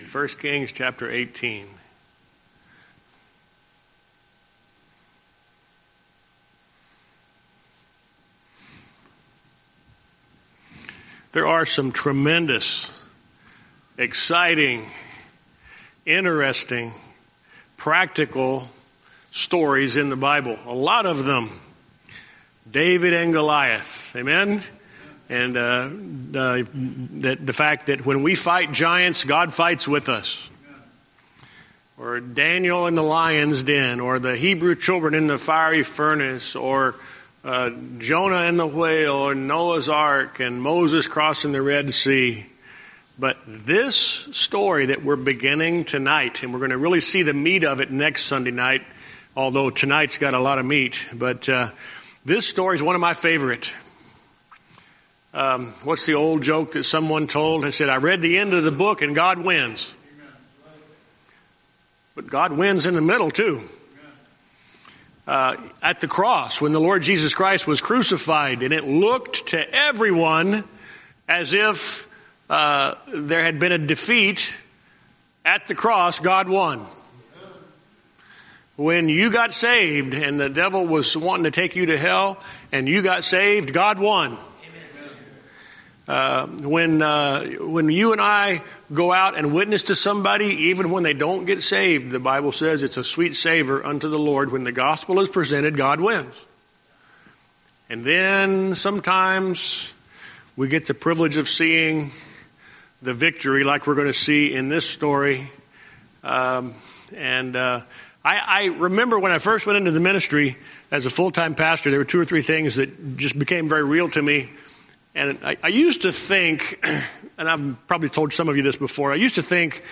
Sermons
Series: Guest Speaker